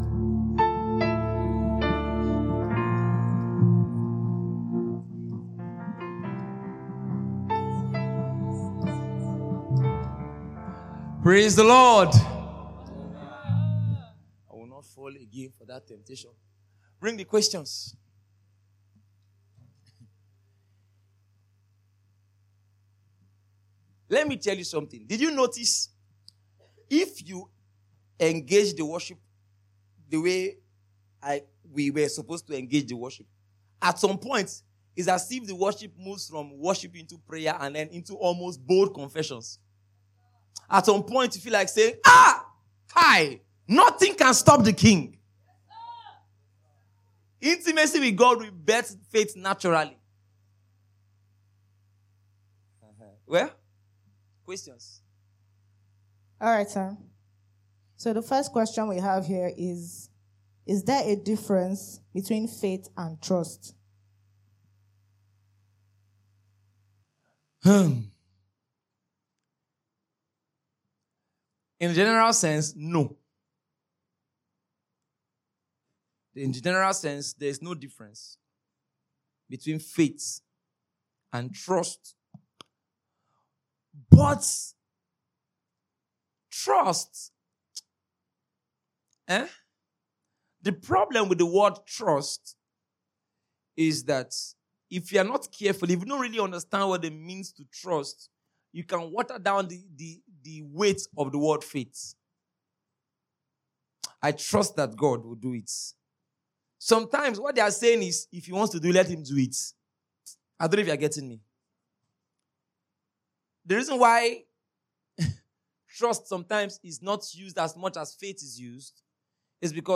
The Word Of Faith Q&A.mp3